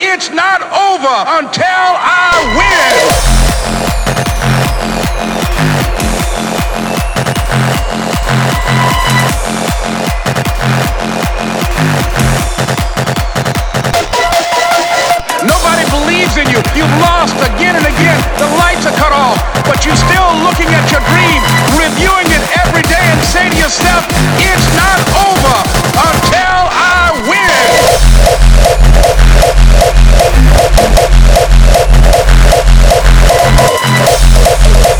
Жанр: Танцевальные